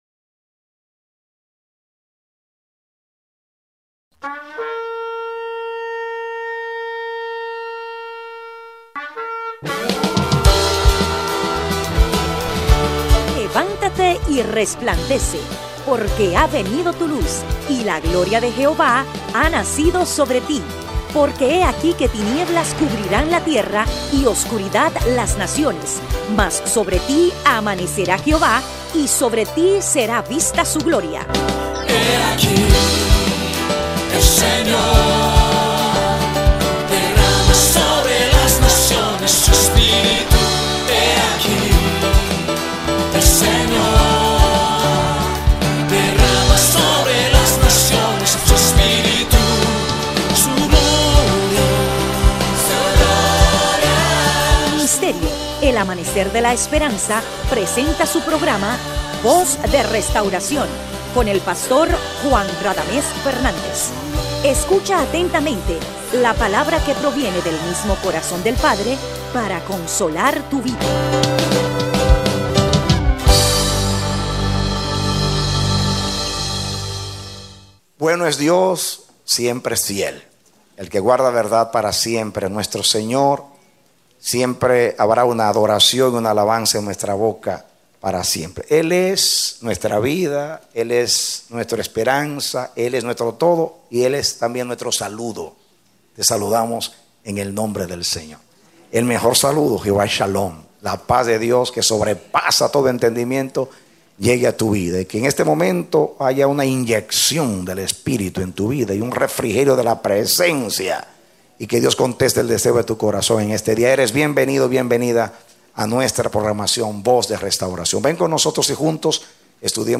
Mensaje: “Nuestro Dios Uno es #12”